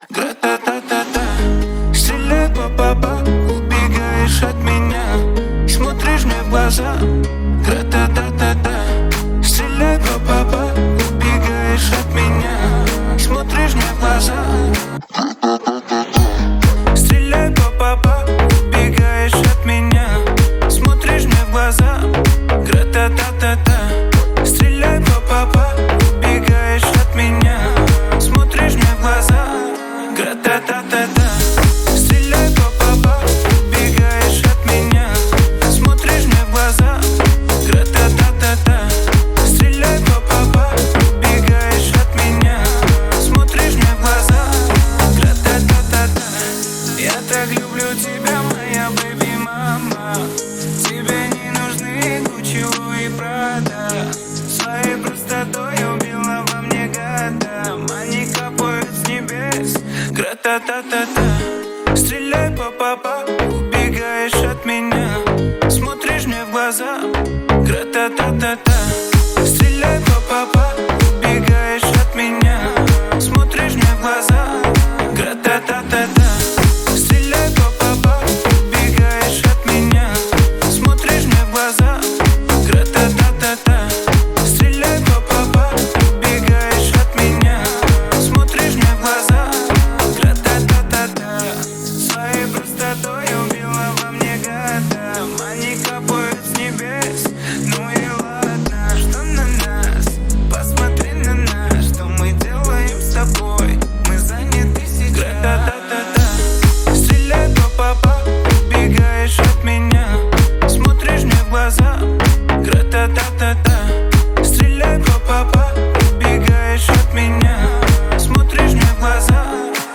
это яркий трек в жанре EDM